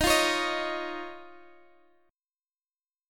Listen to D#7sus2 strummed